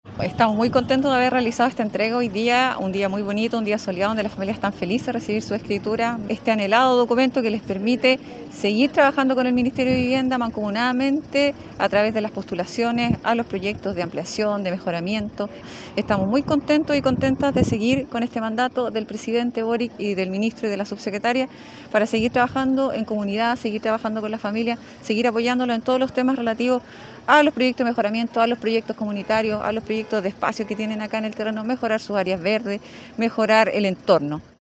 En un maravilloso día de sol, 293 familias curicanas recibieron las escrituras de sus viviendas sociales, documento que las acredita legalmente como propietarias de sus hogares.